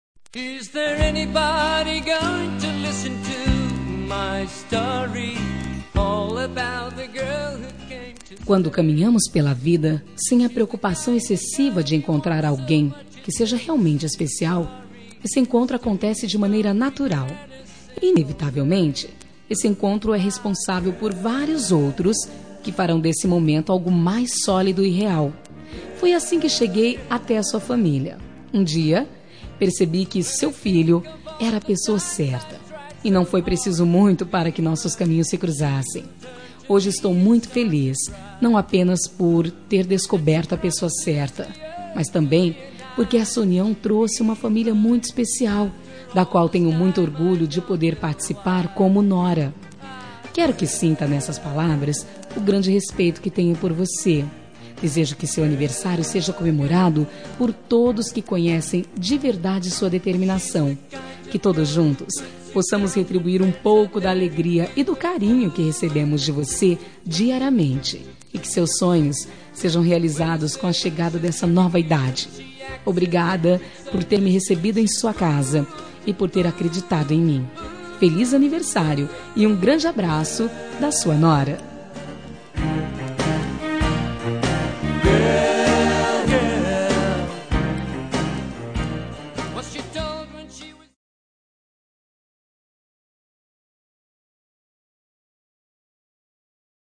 Telemensagem de Aniversário de Sogra – Voz feminina – Cód: 1967